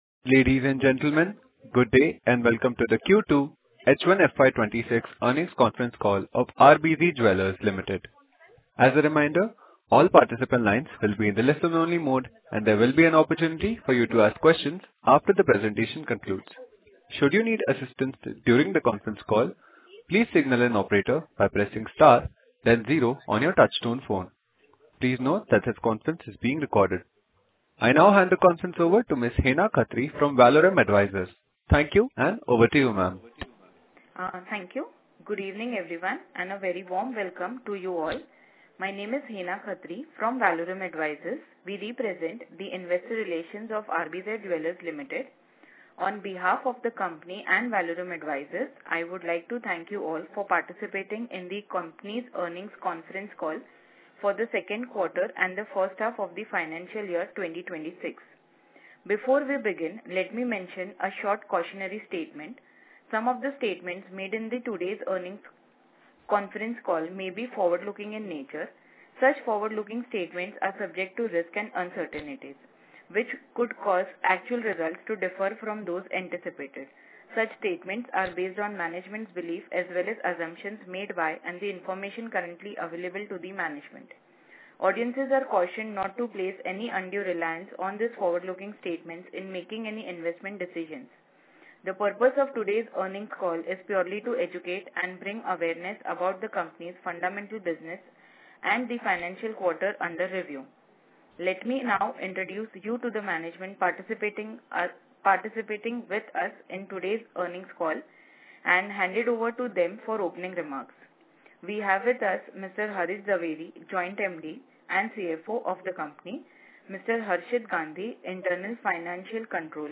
RBZ Jewellers Ltd. | Outcome of conference call with Analysts / Investors – audio recording